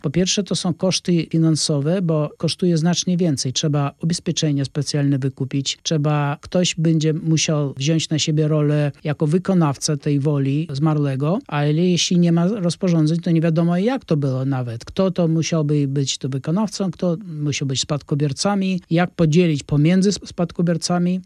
Takie przypadki są niestety częste.